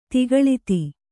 ♪ tigaḷiti